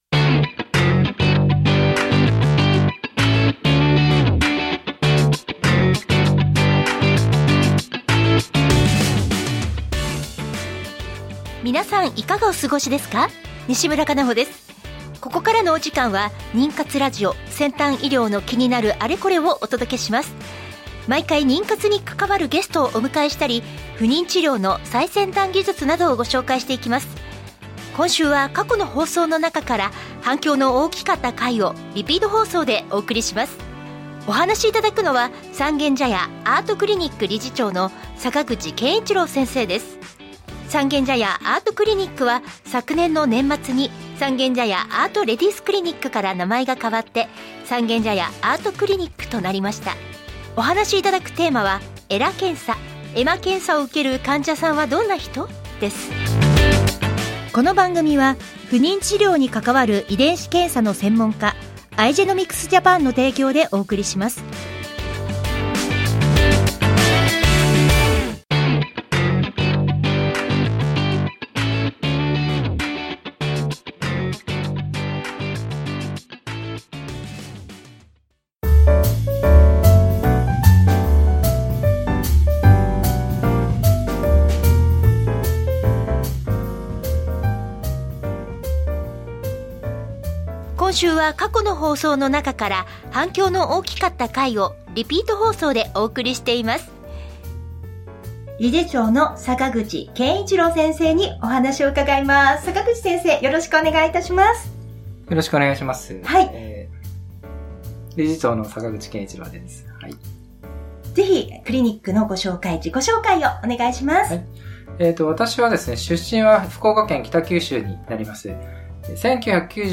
ナビゲーターはフリーアナウンサー